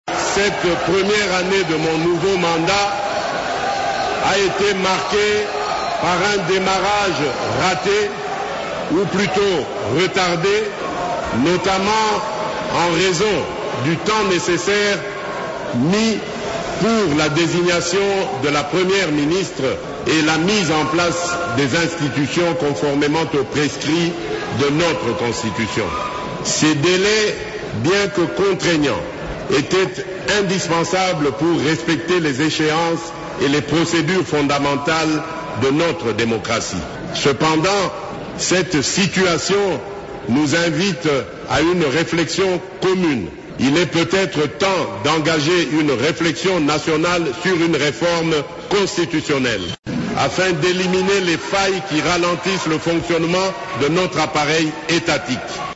Dans son discours sur l’état de la Nation prononcé devant les deux chambres du parlement réunies en congrès mercredi 11 décembre, le président Felix Tshisekedi a invité les Congolais à s'engager dans une réforme constitutionnelle.